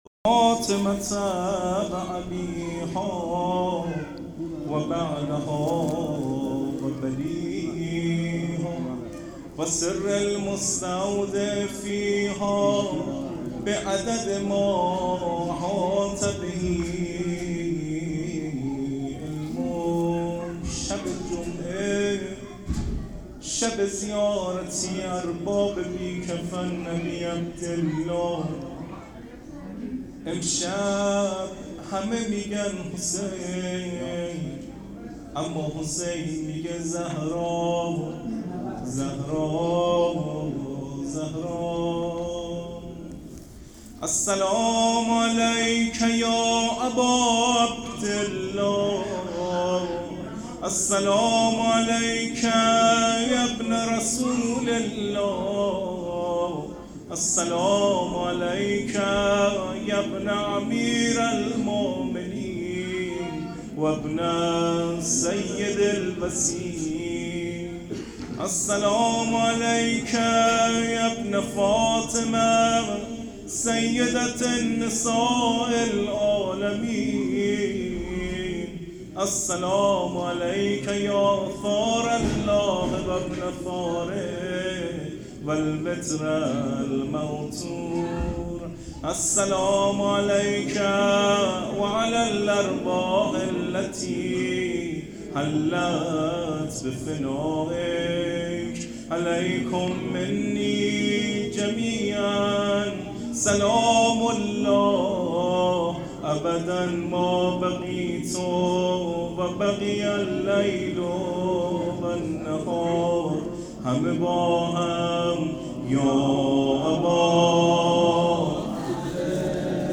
زیارت عاشورا و روضه شهادت حضرت زهرا س